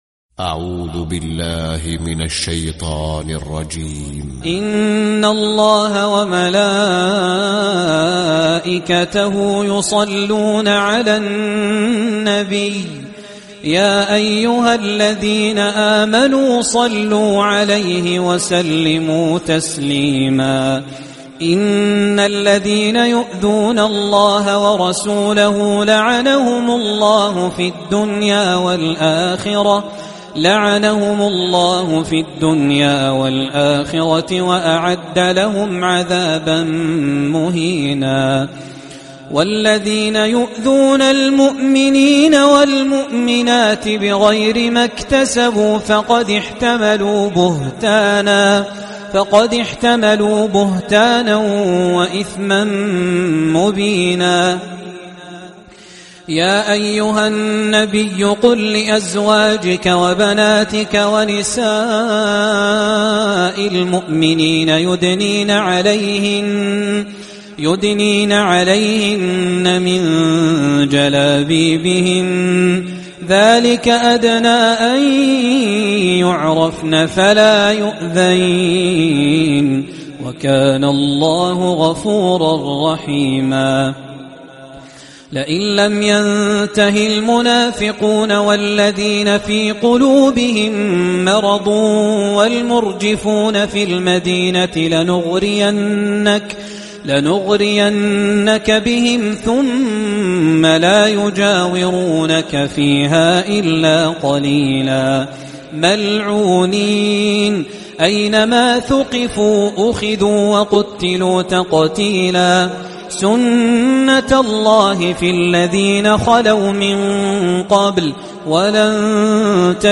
🕋🌙•||تلاوة مسائية||•🌙🕋